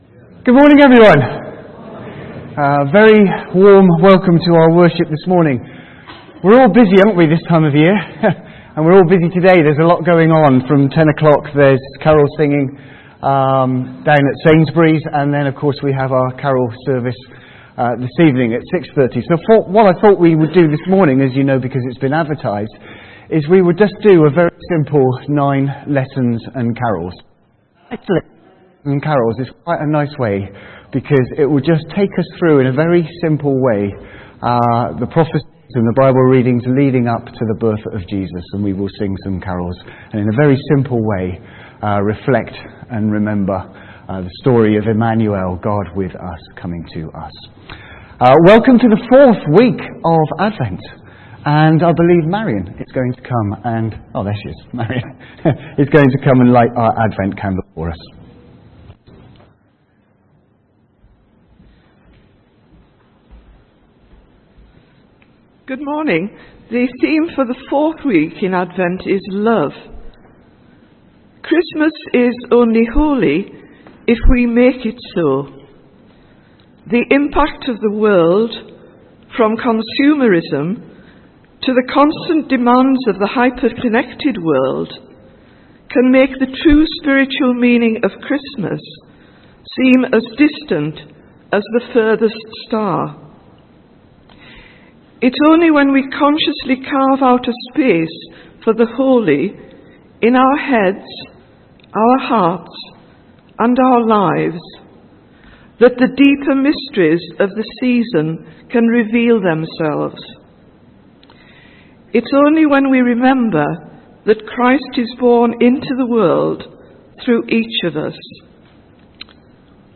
A message from the series
From Service: "9.00am Service"